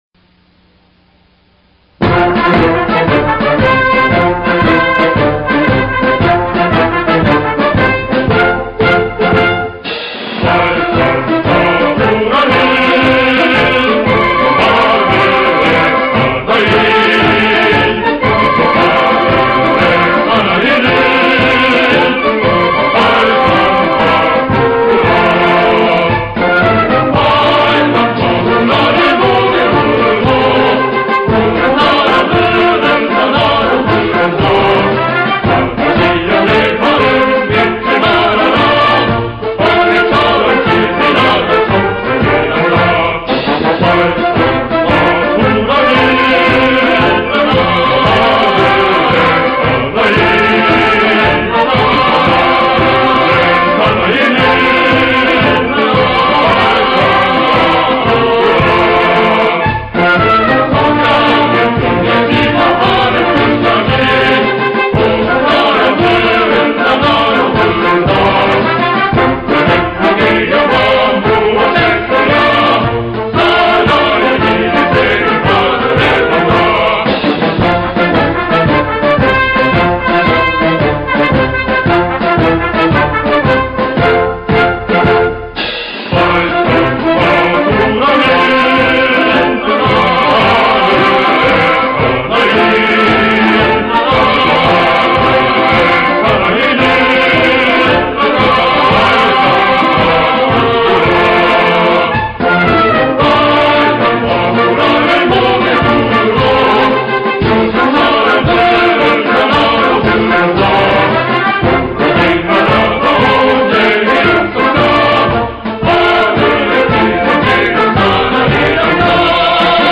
国语版 翻唱歌词：